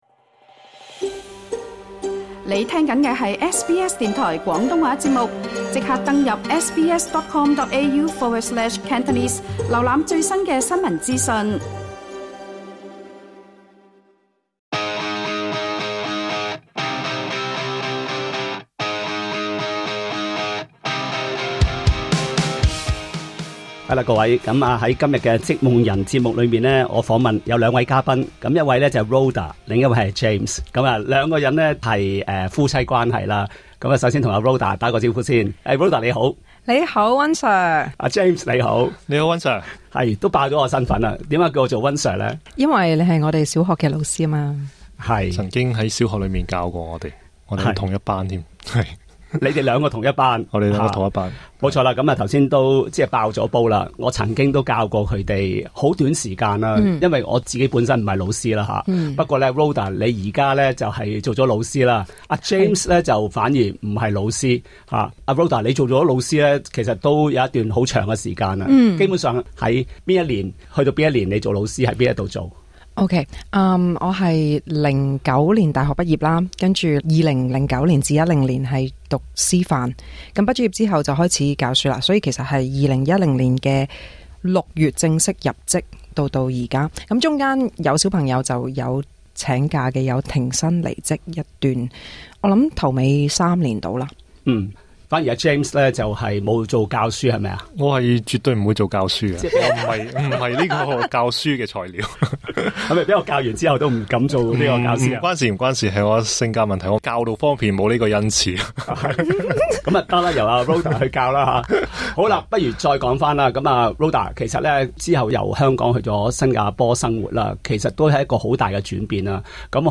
盼這個專訪起鼓勵作用